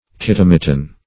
Search Result for " kytomiton" : The Collaborative International Dictionary of English v.0.48: Kytomiton \Ky*tom"i*ton\ (k[-i]*t[o^]m"[i^]*t[o^]n), n. [NL., from Gr. ky`tos a hollow vessel + mi`tos a thread.]
kytomiton.mp3